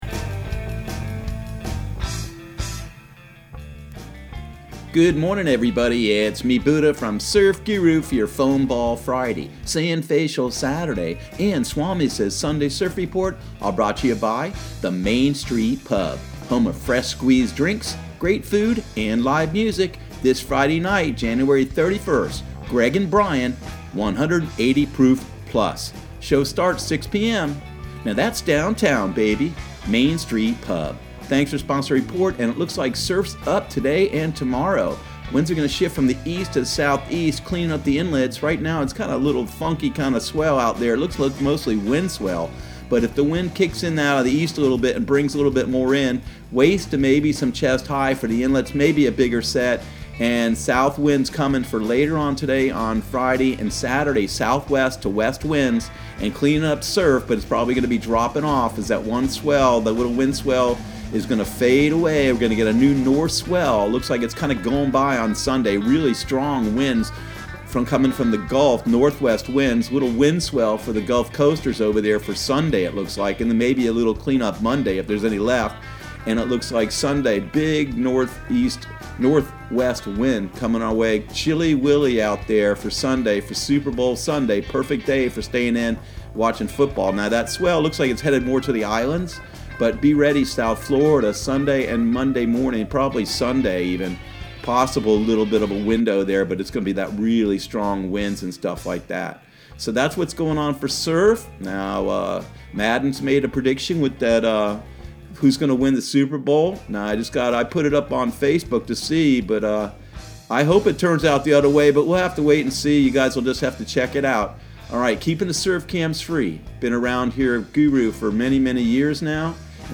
Surf Guru Surf Report and Forecast 01/31/2020 Audio surf report and surf forecast on January 31 for Central Florida and the Southeast.